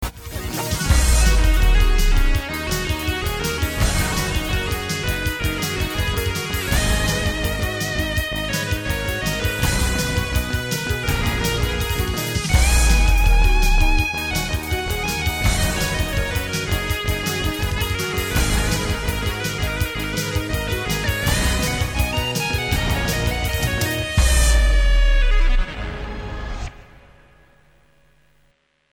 Inga andra tillsatser än JP-8000 och lite taskig spelteknik.
Gitarrsolo
Helsköna demos ! italo möter neue deutsche welle nice !